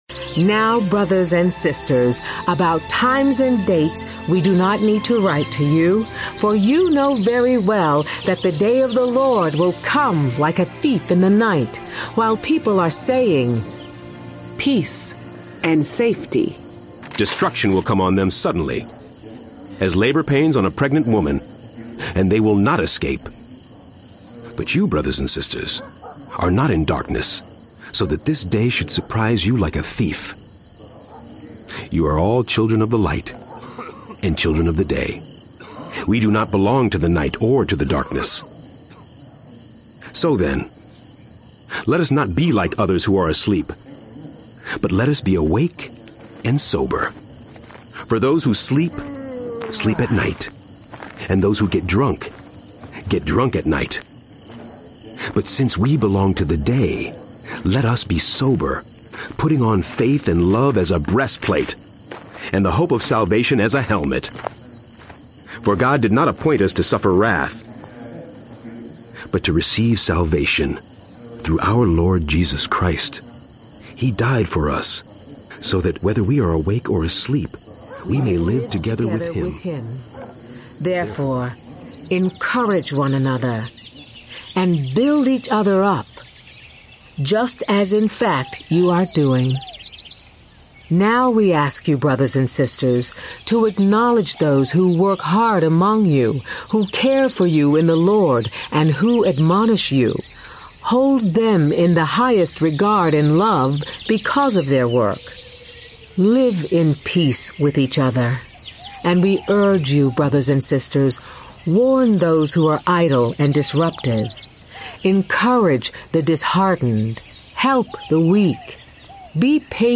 Enjoy 1 and 2 Thessalonians, 1 and 2 Timothy, Titus, and Philemon The most ambitious, critically-acclaimed presentation of the Bible ever produced, Inspired By … The Bible Experience features a star-studded cast of almost 200 performers.
The Bible Experience uses Today’s New International Version (TNIV), and includes dramatic performances set to an original musical underscore by the Prague Symphony Orchestra and Hollywood-style sound design created at Technicolor Studios.
Full Cast
1.3 Hrs. – Unabridged